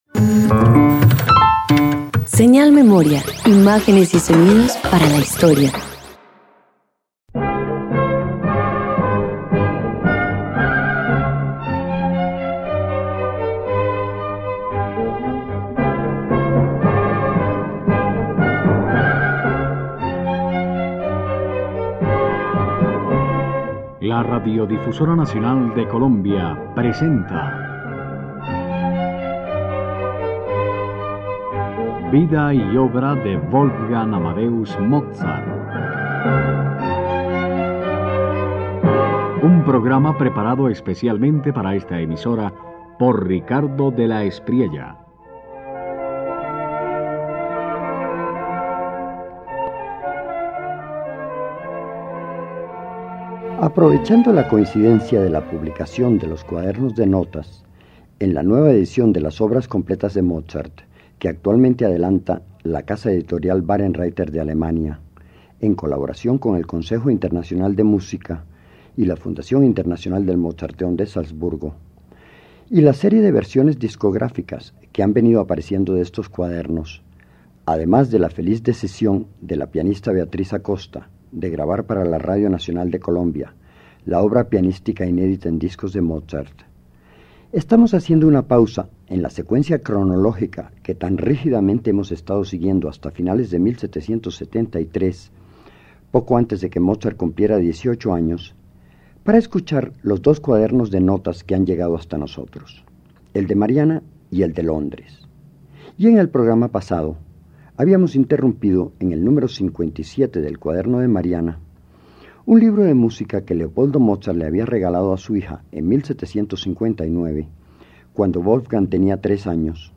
Este episodio presenta el hallazgo histórico de las primeras composiciones de Mozart, páginas perdidas del cuaderno de Mariana que revelan el origen documentado de su genio. Obras interpretadas por primera vez en exclusiva para Radio Nacional.